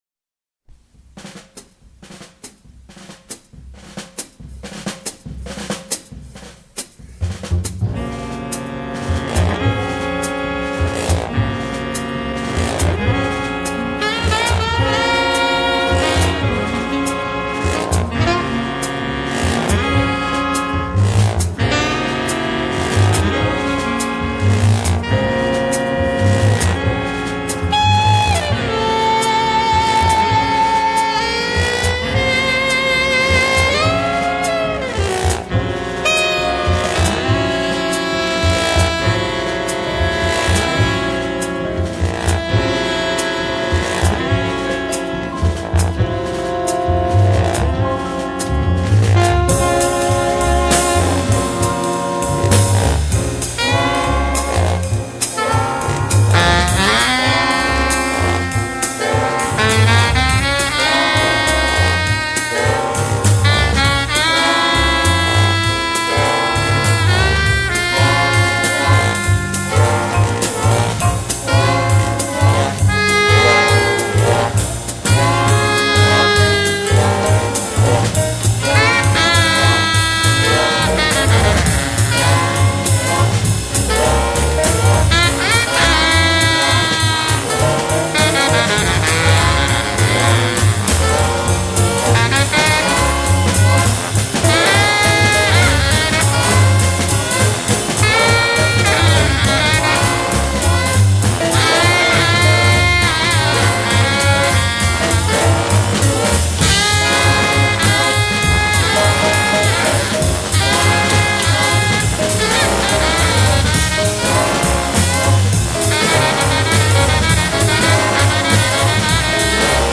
Avant-Garde Jazz